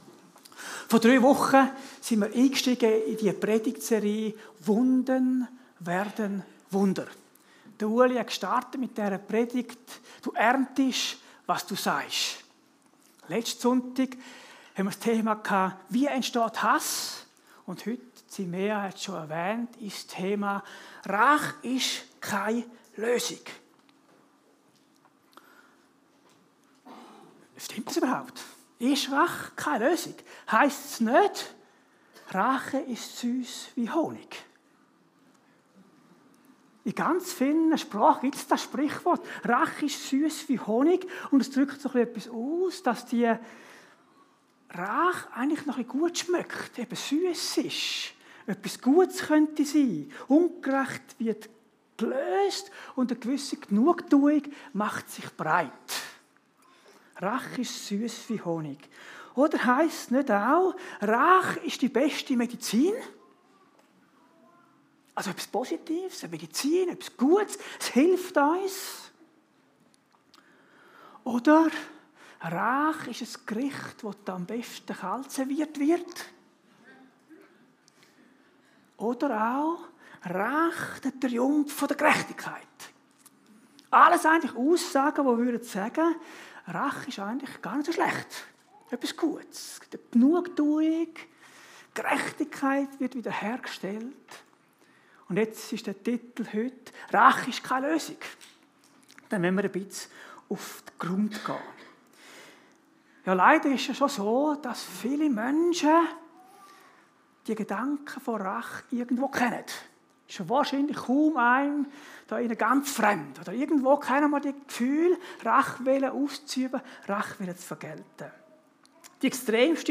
Predigt Zwischen Begierde und Heiligkeit – ein alltägliches Spannungsfeld